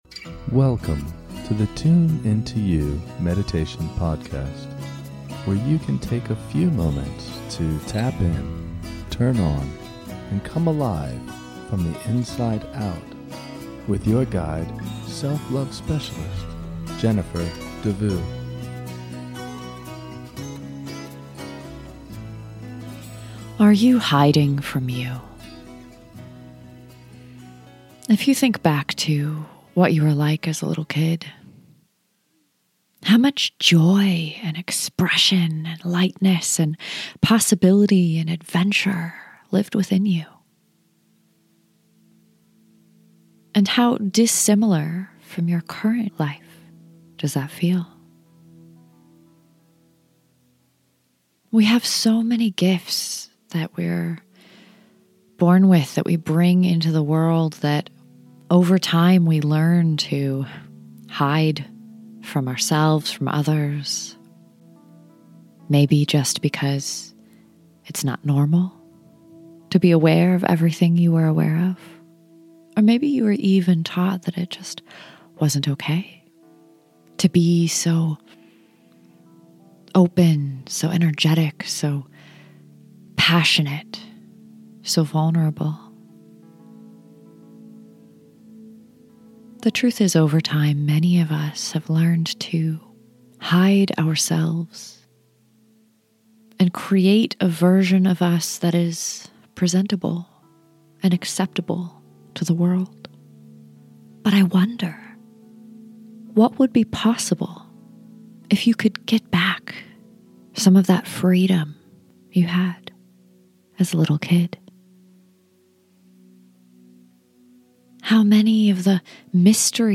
This is a short guided meditation to explore and bring forward the parts of you that you left behind to fit in. We will heal and reconnect to the light and vitality within you.